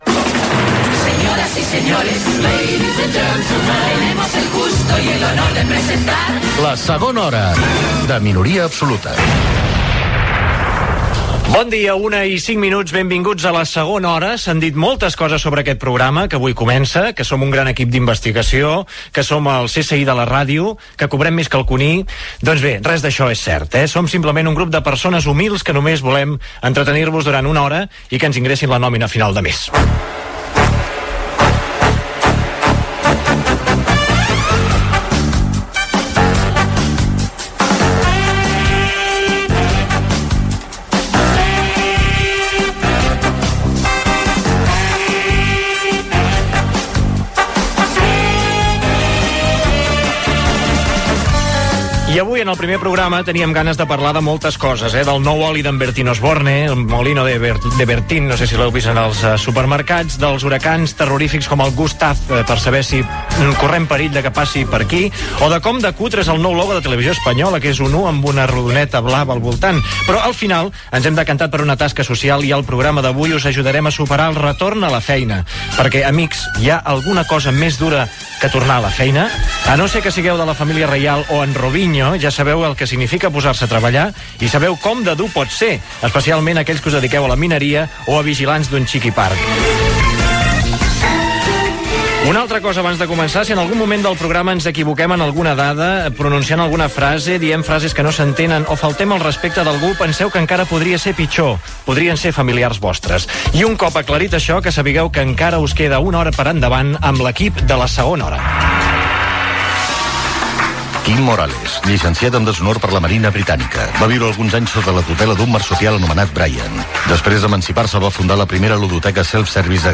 692b122e84f4f0b53e0af2a210459e806e4b8dcc.mp3 Títol RAC 1 Emissora RAC 1 Barcelona Cadena RAC Titularitat Privada nacional Nom programa La segona hora Descripció Primera edició del programa. Careta del programa, hora, presentació, tema escollit per fer el programa: el retorn a la feina.
Entreteniment